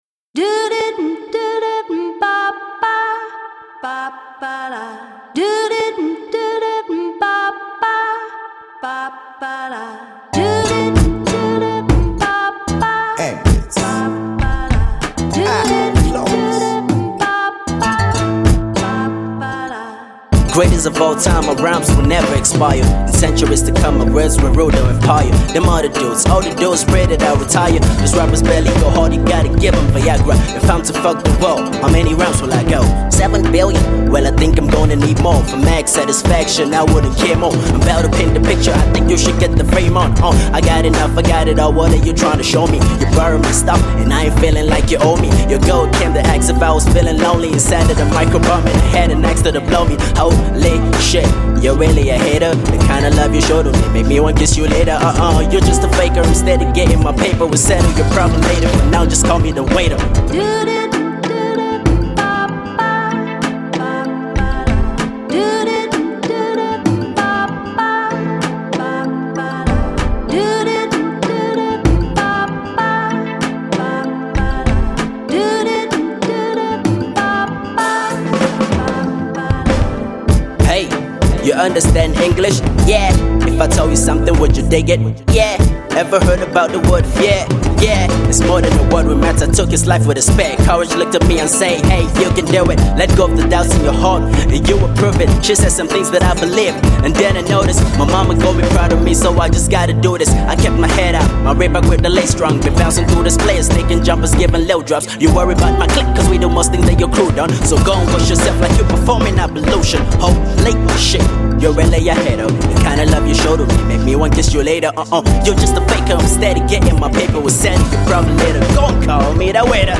Hip-Hop
Rap song